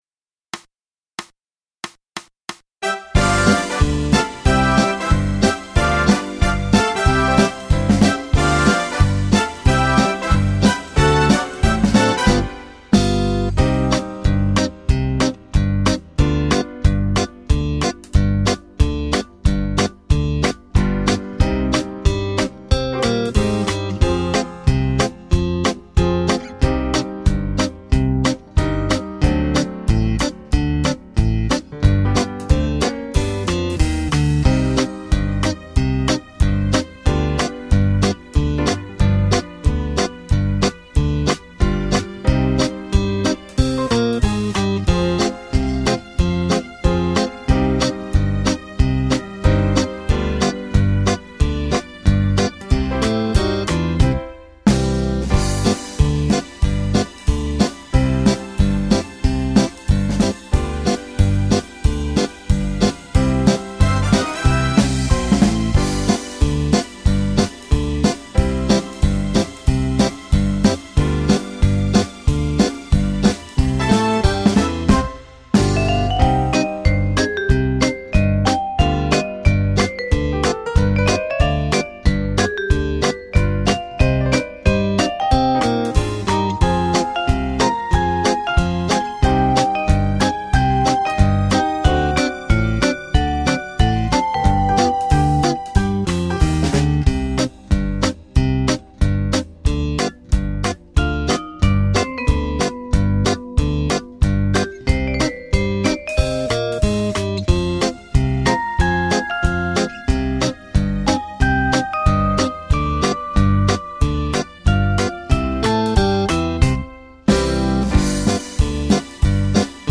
Genere: Fox
Scarica la Base Mp3 (2,72 MB)